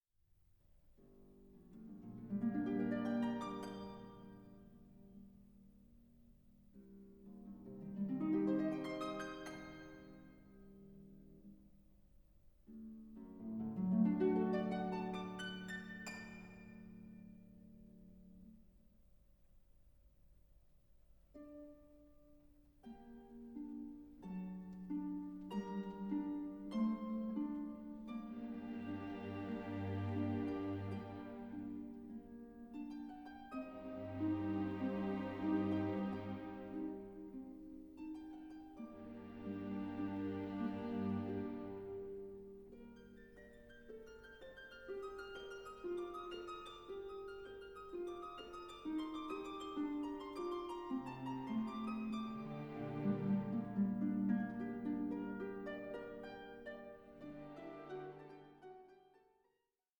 Rondo